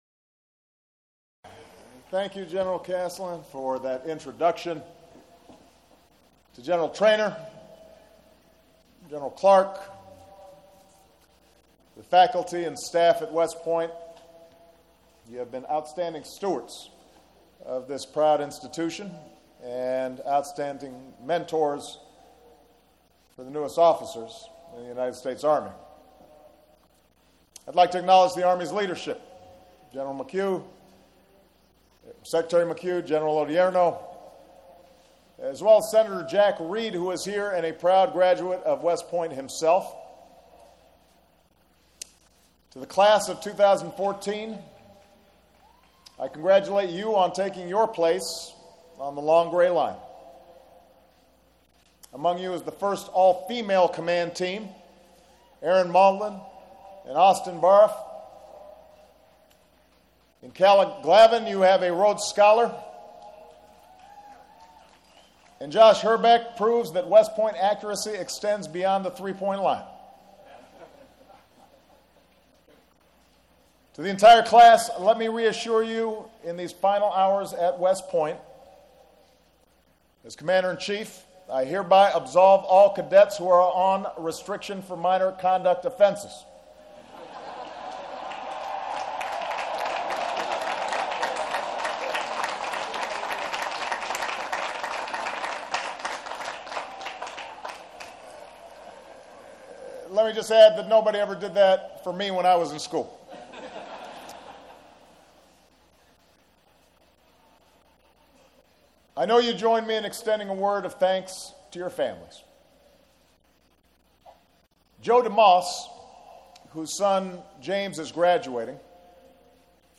U.S. President Barack Obama delivers the commencement address at the U.S. Military Academy